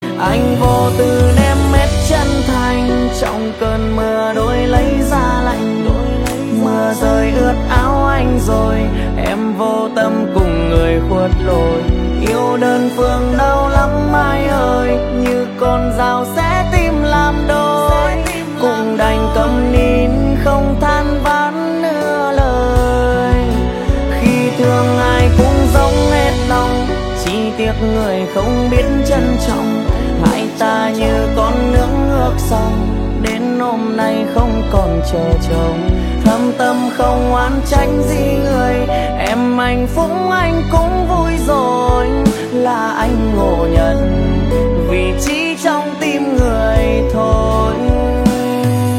Nhạc Chuông Nhạc Trẻ